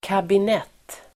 Uttal: [²kabin'et:]